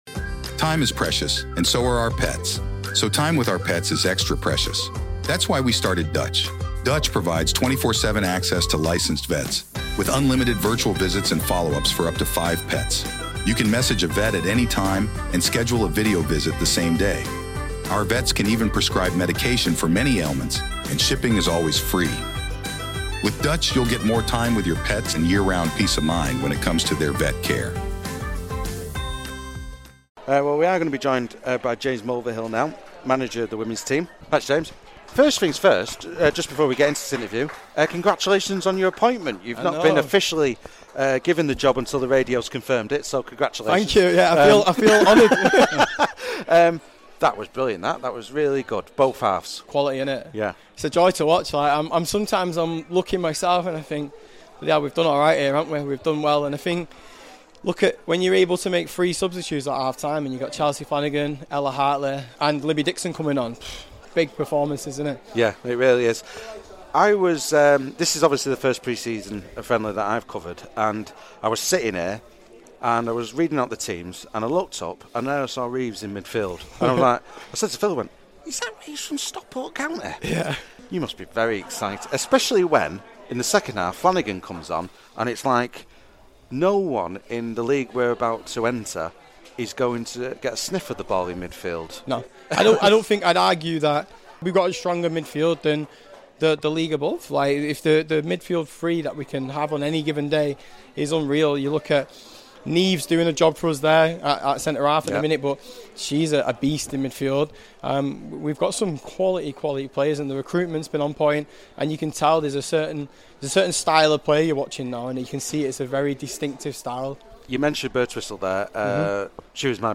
FCUM Radio / Post Match Interview